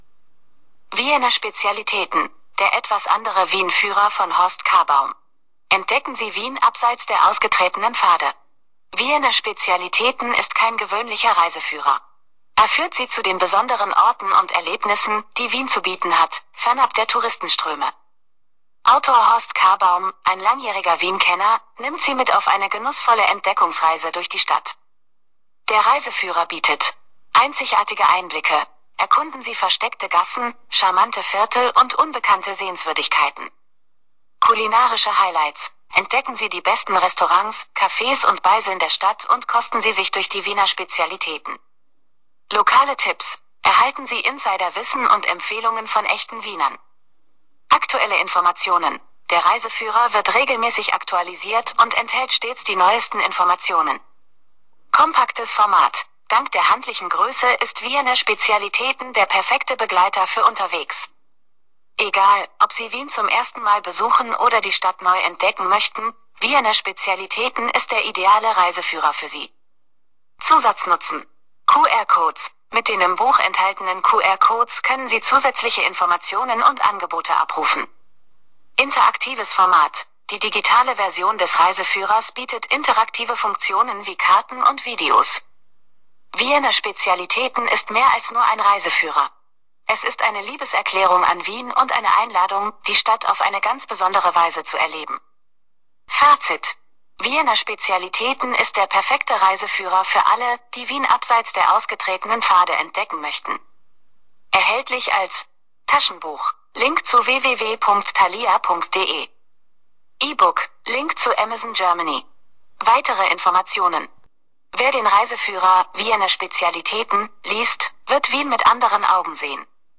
... das denkt die AI Google-Gemini darüber